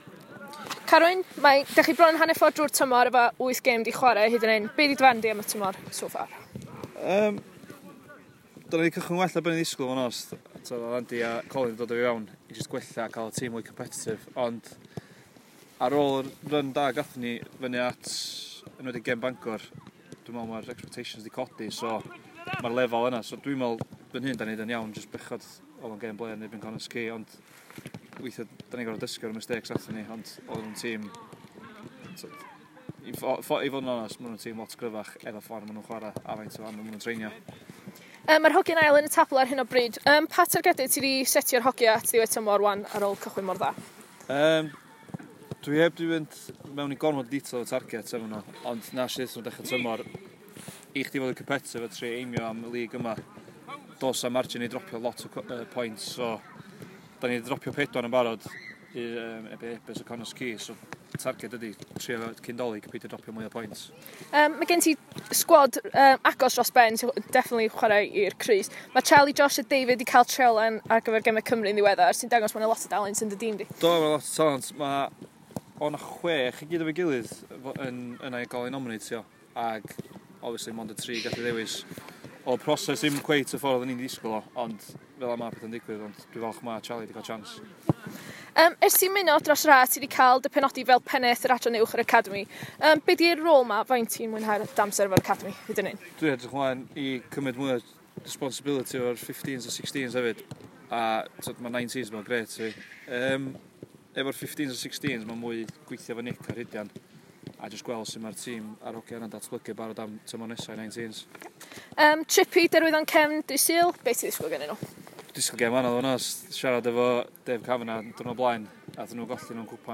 Cyfweliad